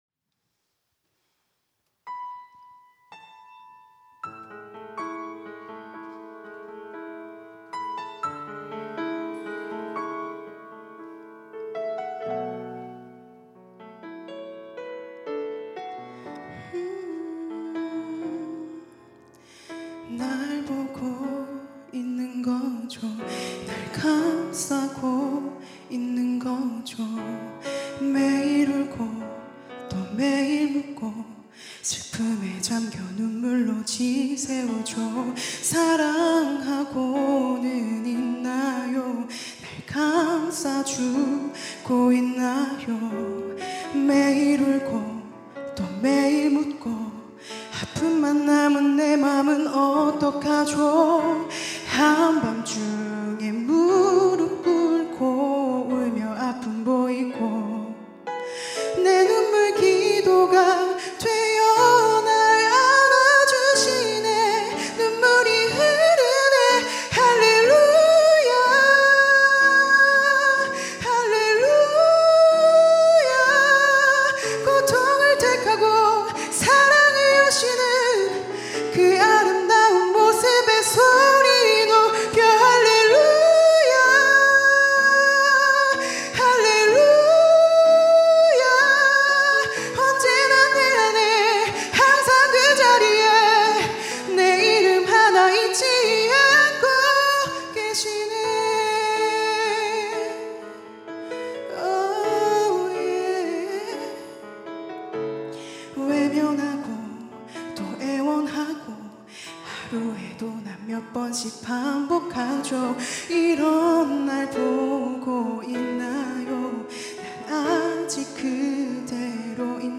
특송과 특주 - 할렐루야